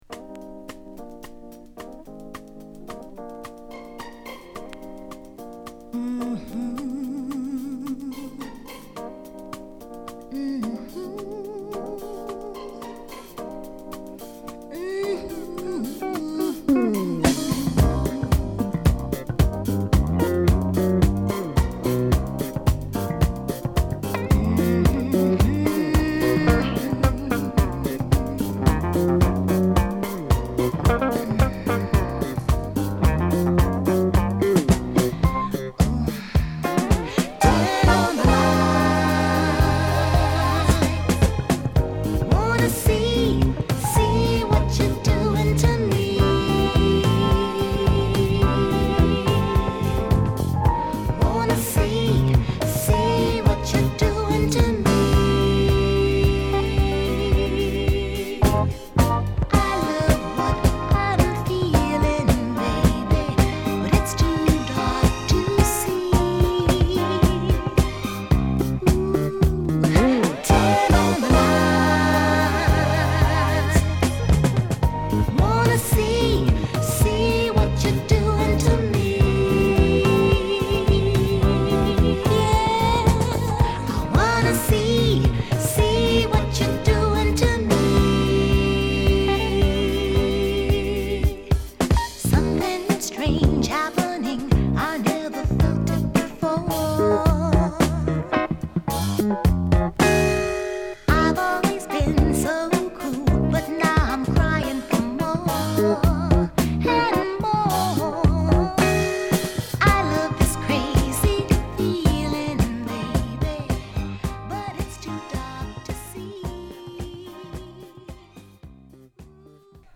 メロウも良いですよ。